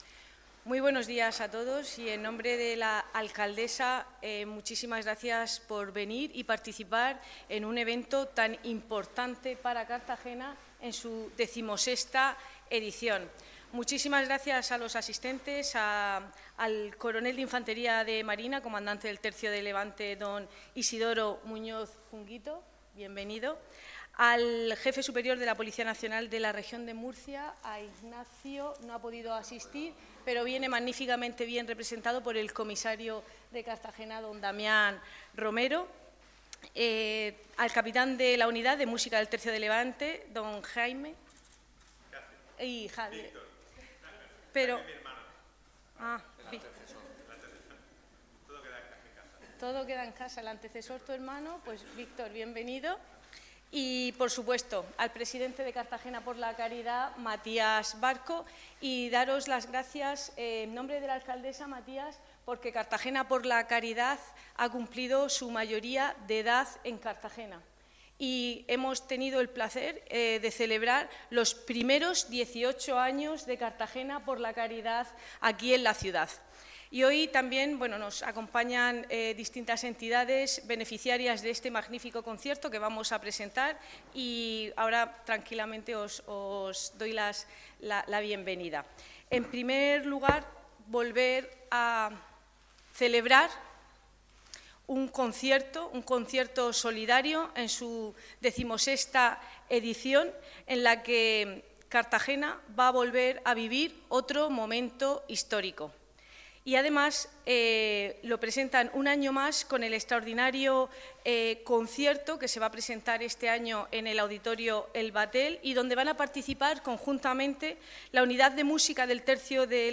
Enlace a Presentación del Concierto Benéfico Cartagena por la Caridad